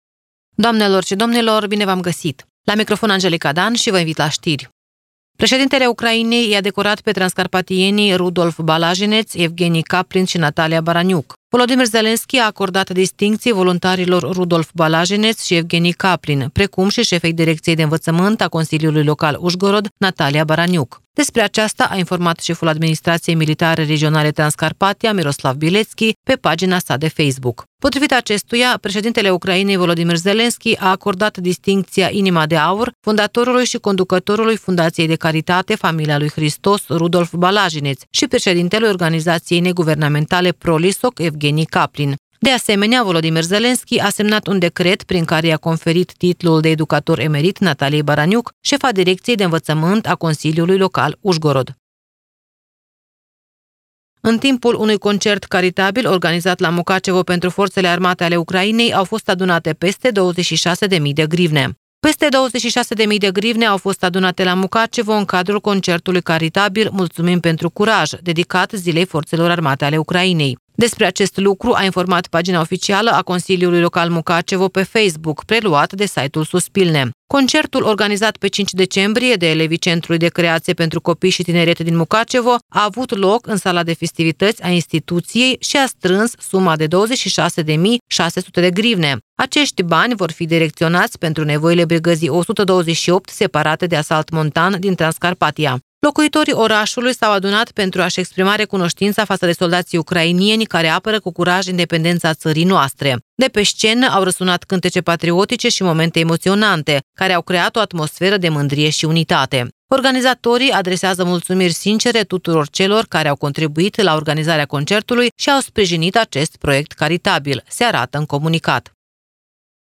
Știri Radio Ujgorod – 09.12.2024, ediția de seară